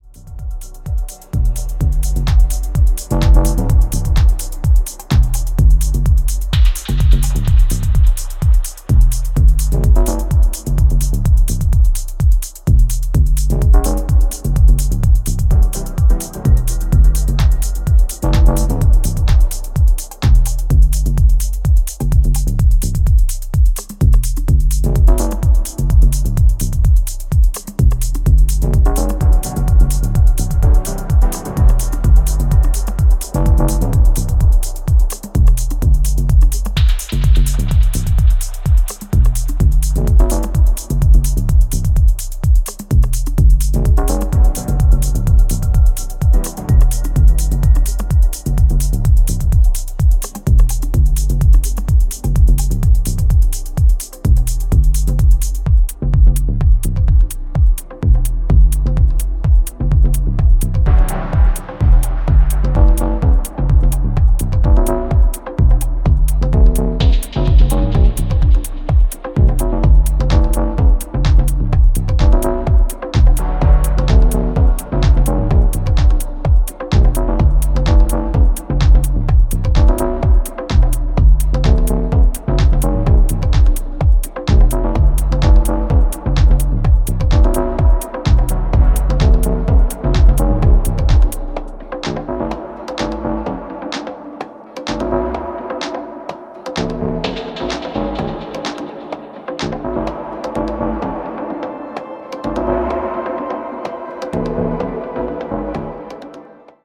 冷ややかなダブエフェクトが緊迫感を煽る
各曲アプローチを絶妙に変えながらもアトモスフェリックかつ幻想的なトーンを保ち続けていますね。